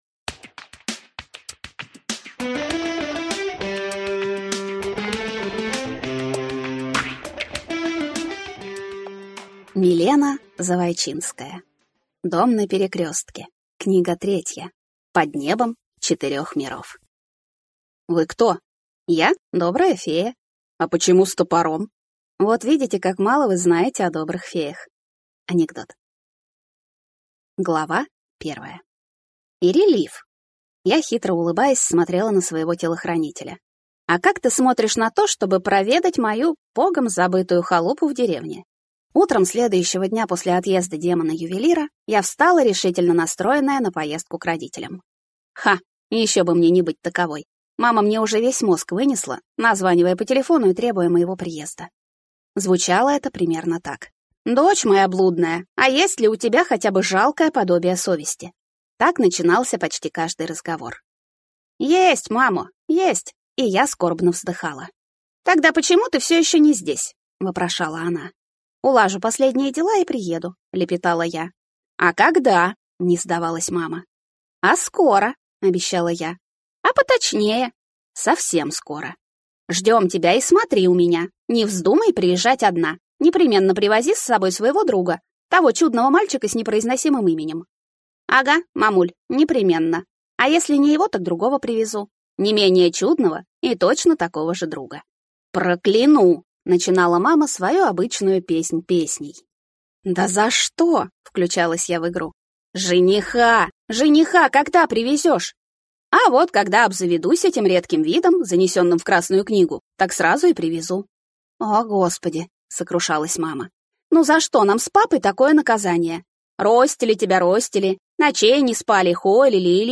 Аудиокнига Дом на перекрестке. Под небом четырех миров | Библиотека аудиокниг